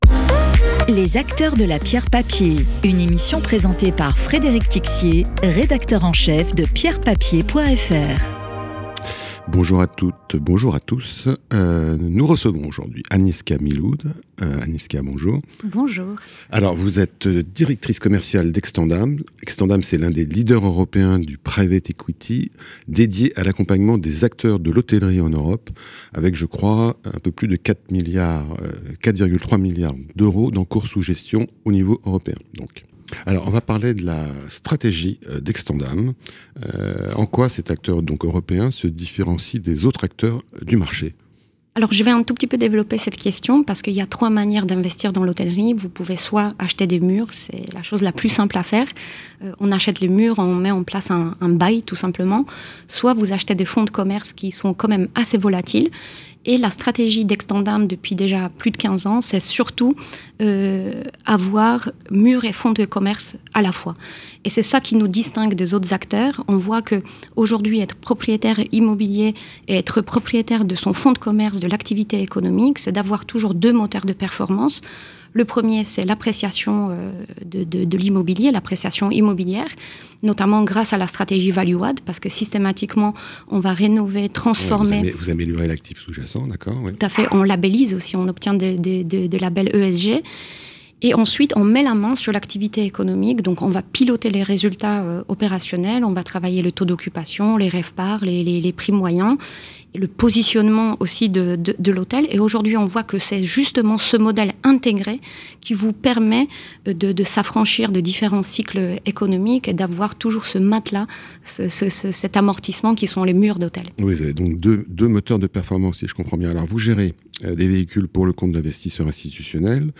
Podcast d'expert
Interview.